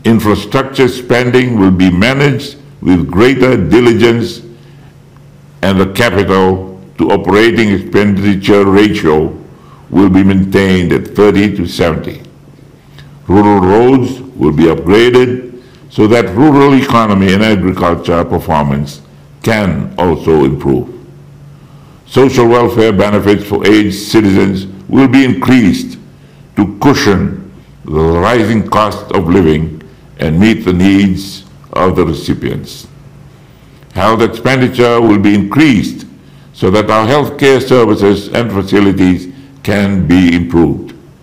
While delivering the state of the nation’s address, Rabuka says the 2023-24 national budget will be people-centered and adjustments will be made to ensure sustainable economic growth in the short and medium term.